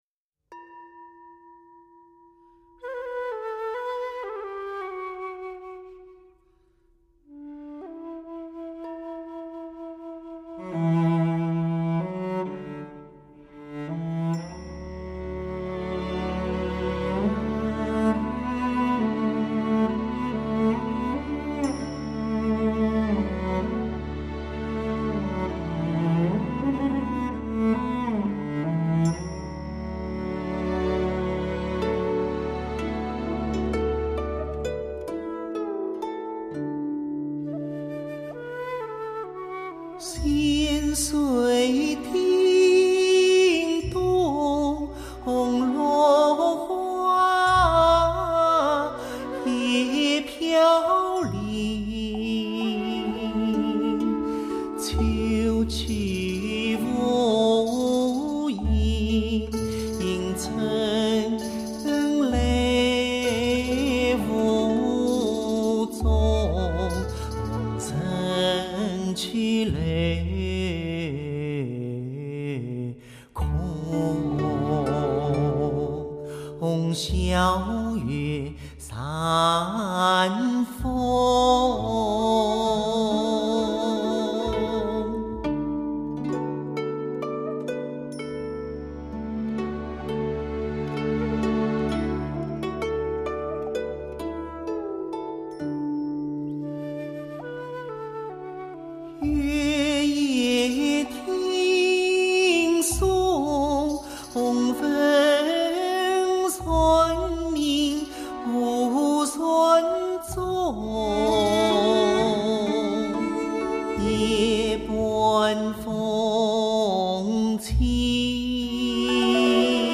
演曲逞技,乐贯中西创意十番绝唱
依字行腔,戏韵唐宋凝神千秋名篇
戏曲各剧种十名角耀眼登场